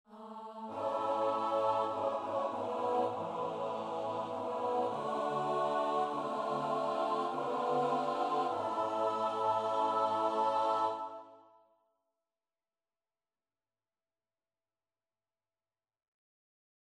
Key written in: F# Major
How many parts: 4
Type: SATB
All Parts mix:
Learning tracks sung by